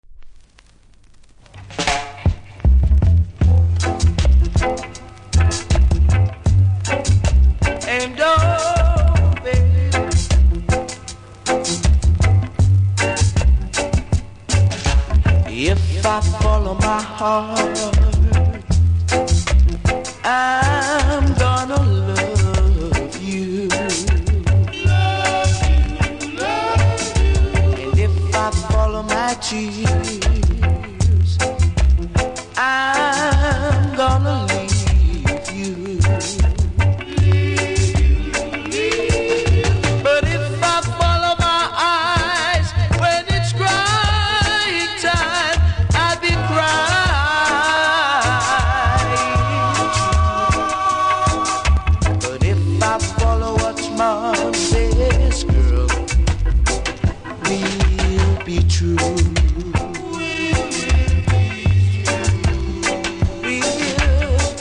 プレス起因の凹でノイズ拾います。